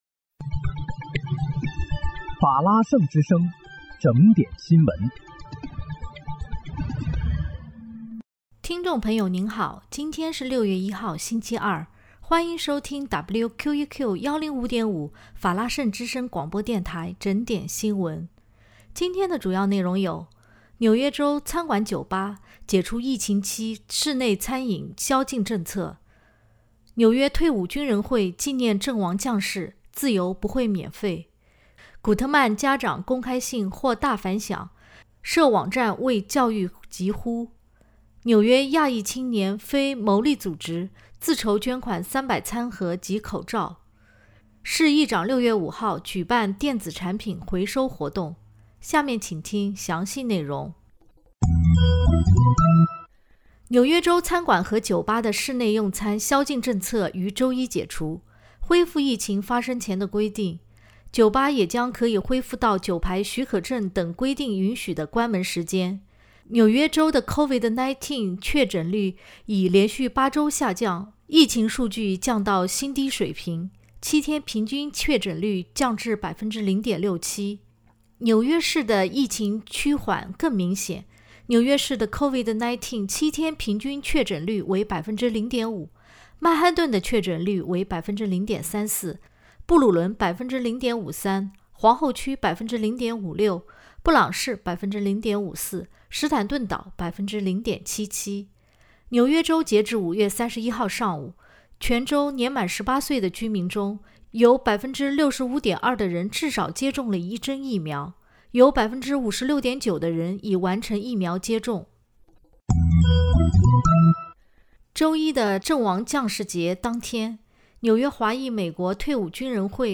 6月1日（星期二）纽约整点新闻